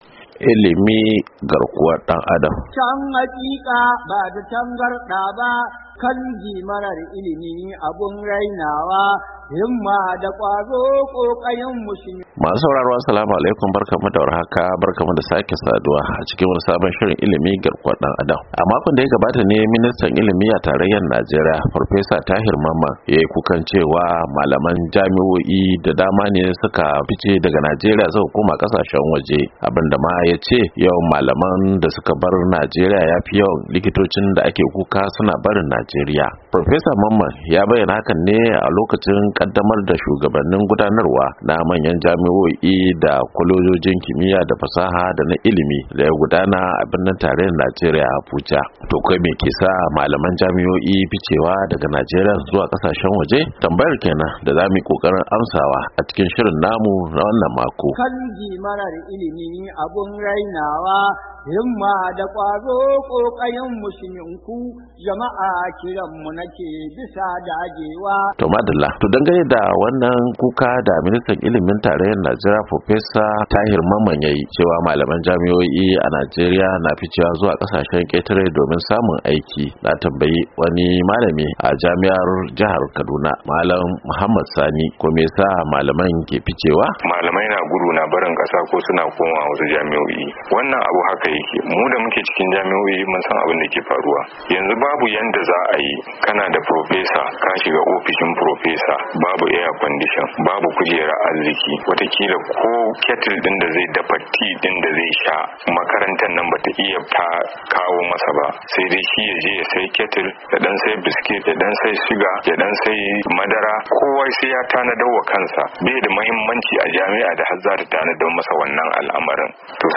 A shirin Ilmi na wannan makon mun tattauna ne da wasu malaman jami’o’i da masu ruwa da tsaki akan batun ministan Ilimi, Tahir Mamman, wanda ya fada a ranar Alhamis cewa tsarin ilimi a Najeriya ya fi fama ne da matsalar hijirar kwararru zuwa kasashen waje.